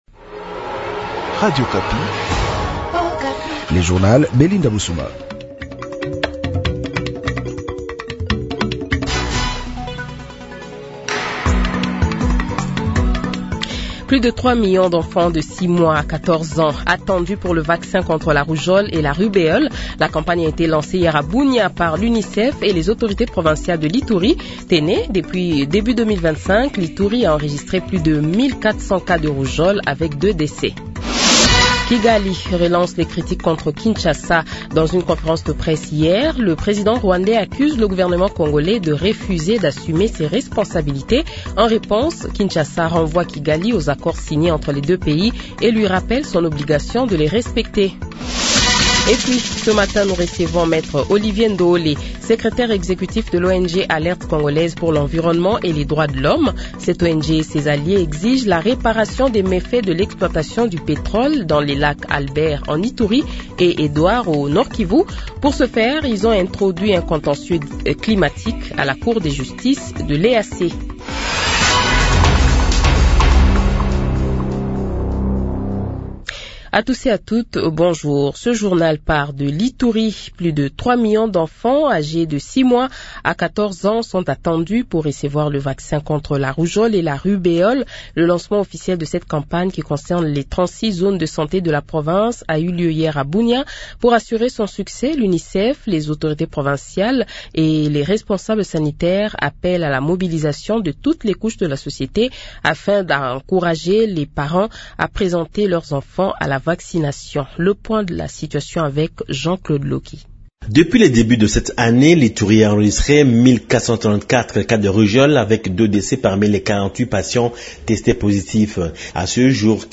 Le Journal de 8h, 28 Novembre 2025 :